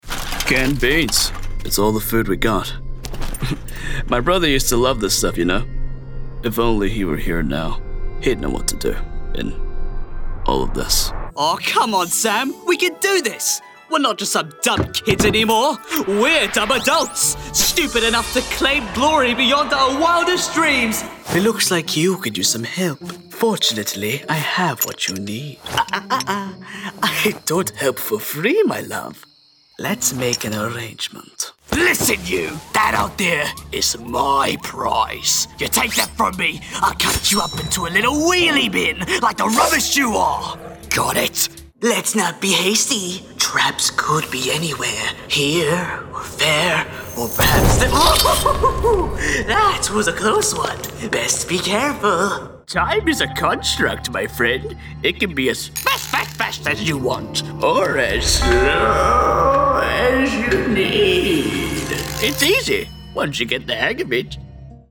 GAMING 🎮